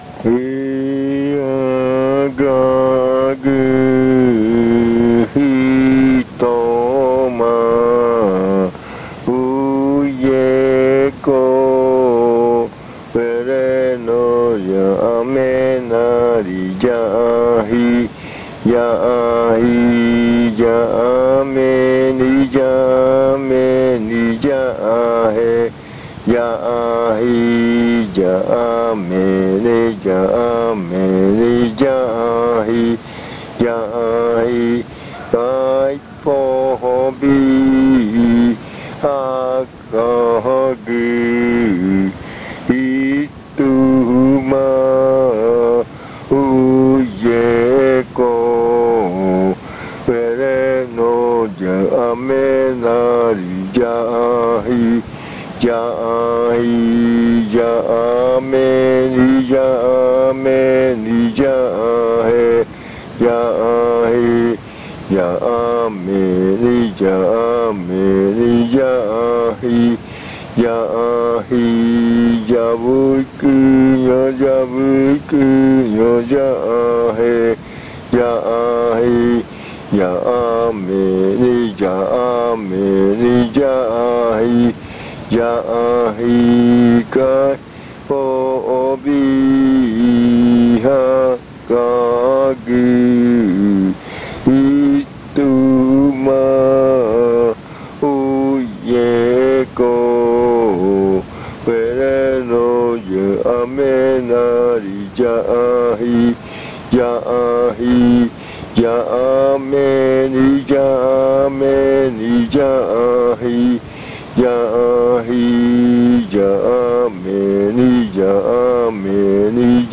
Leticia, Amazonas
Canto hablado (uuriya rua o fakariya rua)..
Spoken chant (uuriya rua or fakariya rua)..